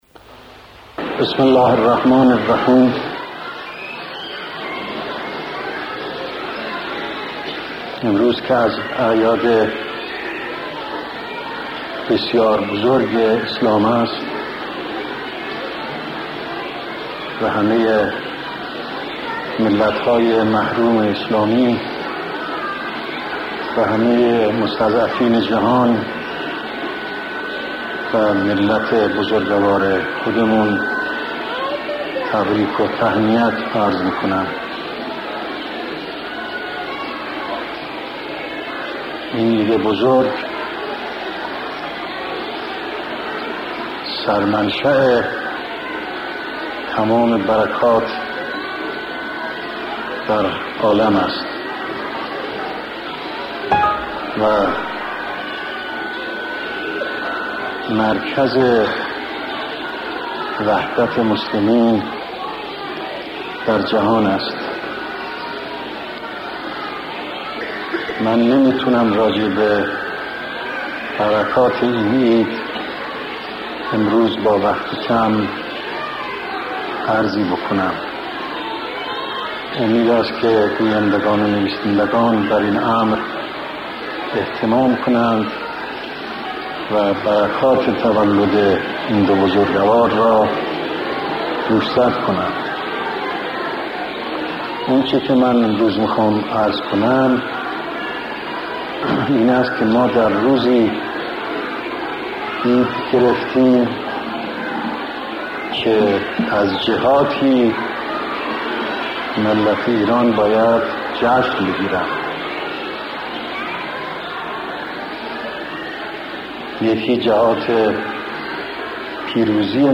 سخنرانی در جمع خانواده‌های شهدا و مسئولان قضایی ارتش (بیداری ملت)